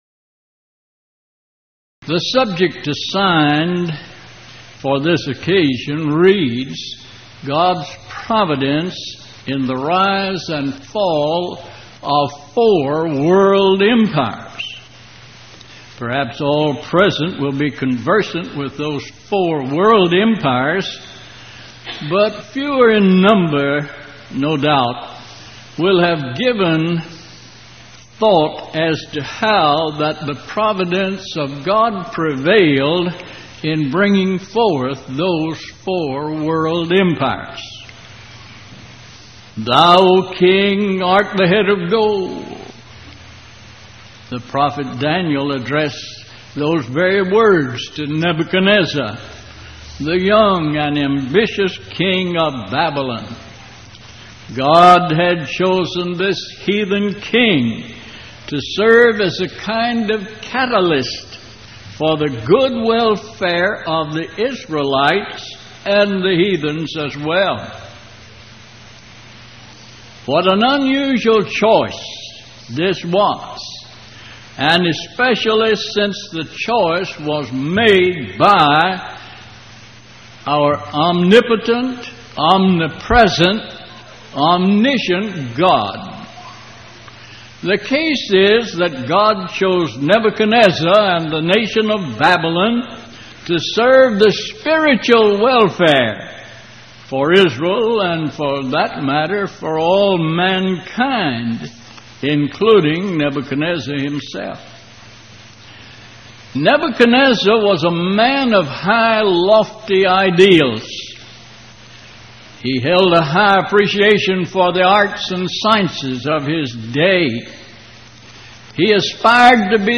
Series: Power Lectures Event: 1989 Power Lectures